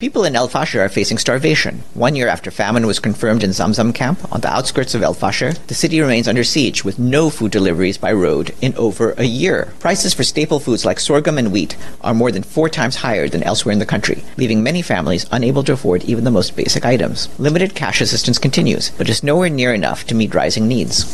during a press briefing